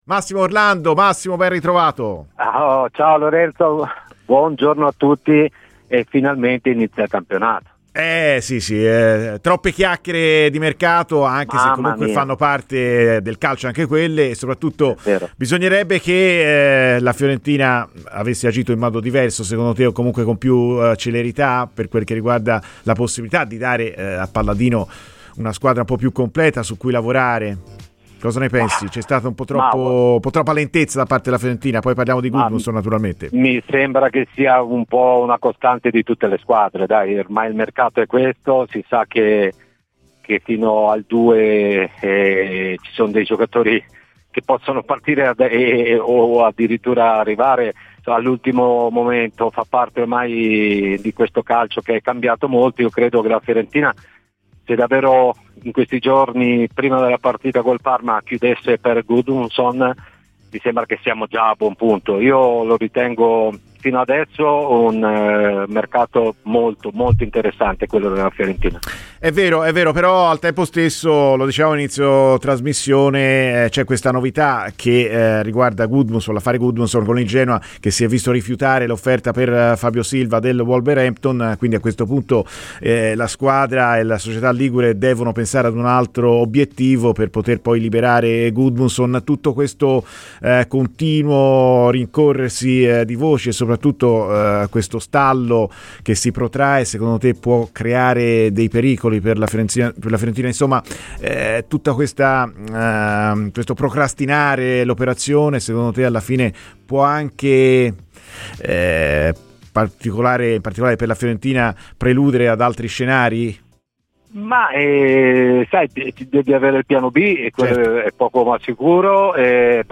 Ascolta il podcast per l'intervista integrale.